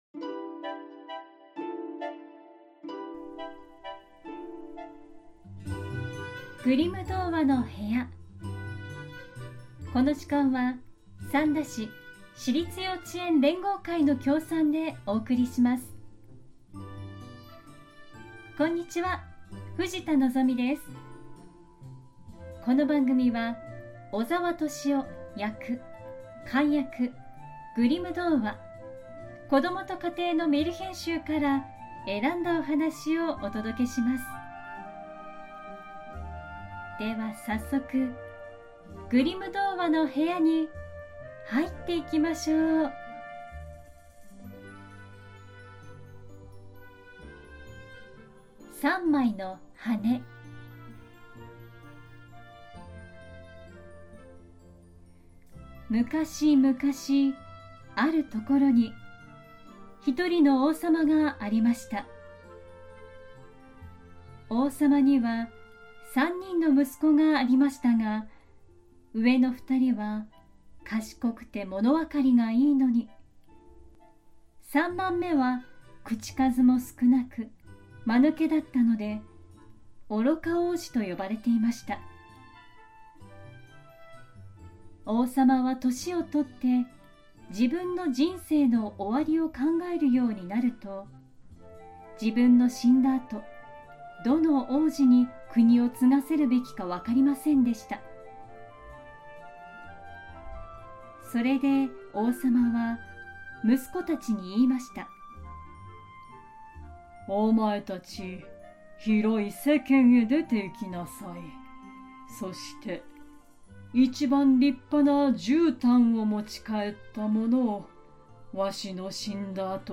グリム兄弟によって集められたメルヒェン（昔話）を、翻訳そのままに読み聞かせします📖 今回お届けするのは『三まいの羽』。 王さまの跡継ぎを決めるため三人の息子に問題を課し、息子たちは「三まいの羽」が飛んで行った方にそれぞれ向かって最も素晴らしいじゅうたんや指輪などお題のものを探します。